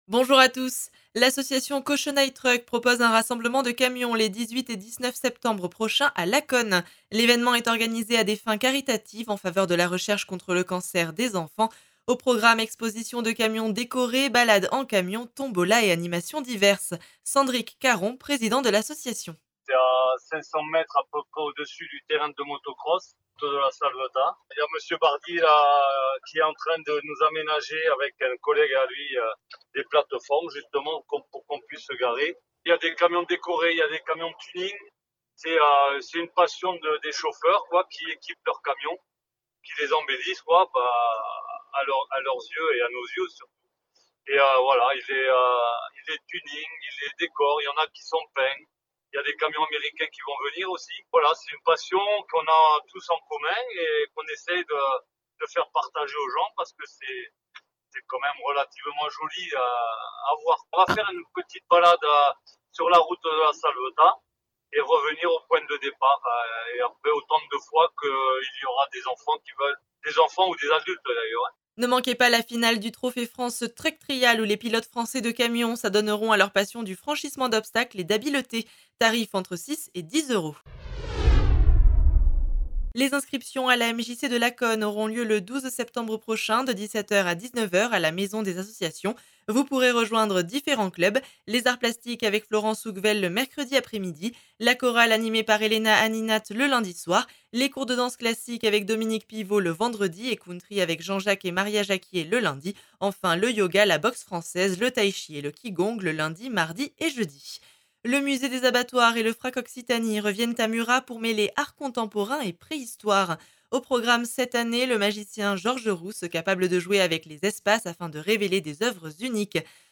Actualités